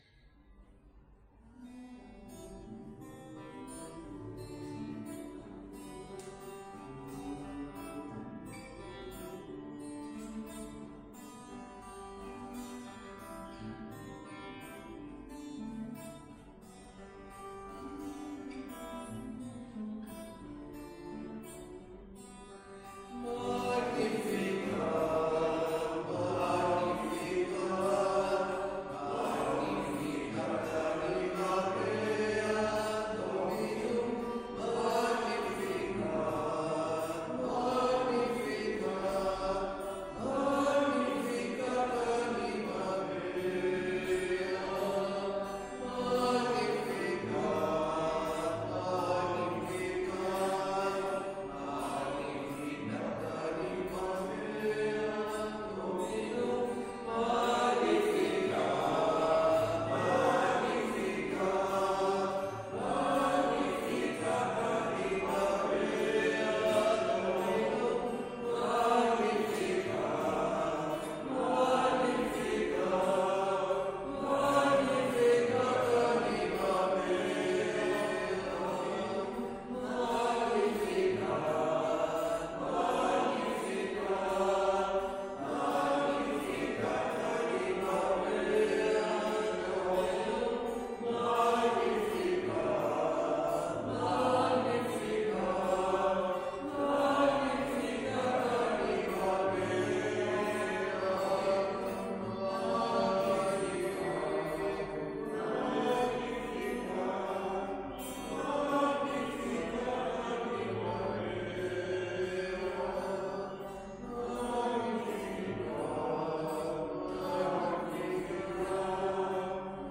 Església del Sagrat Cor - Diumenge 29 de juny de 2025
Vàrem cantar...